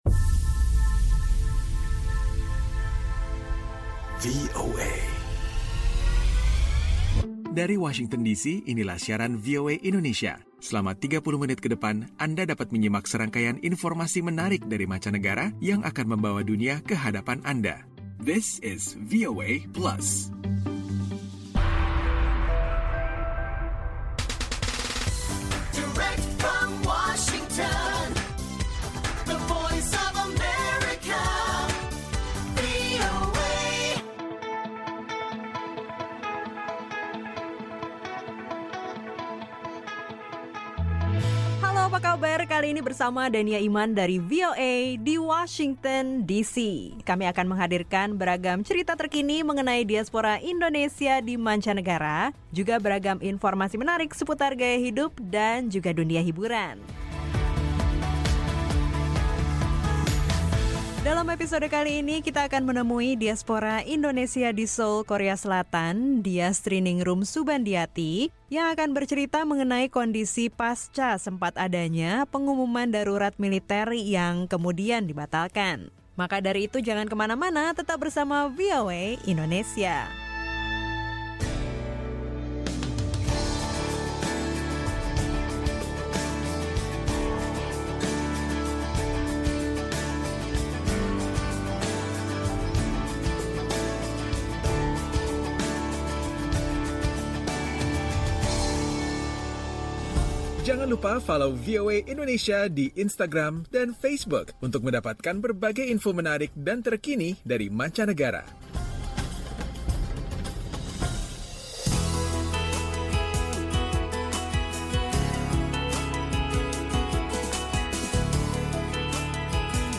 Mengulas obrolan bersama seorang diaspora Indonesia yang merupakan pebisnis restoran dan travel haji, sekaligus mahasiswi Indonesia, dan tinggal di kota Seoul, Korea Selatan mengenai situasi di tempat tinggalnya belum lama ini saat sempat ada pengumuman darurat militer.